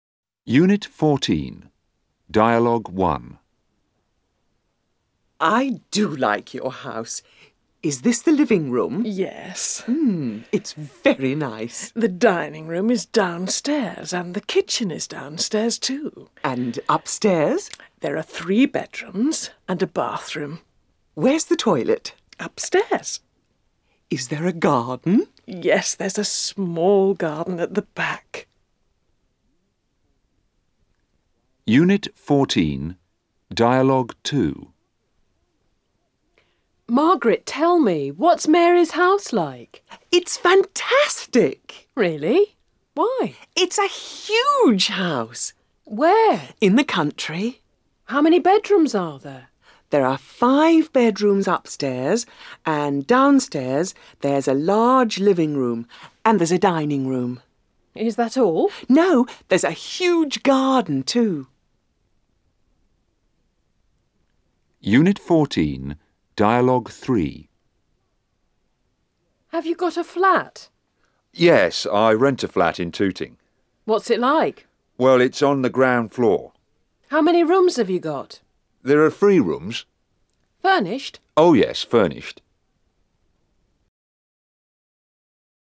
07 - Unit 14, Dialogues.mp3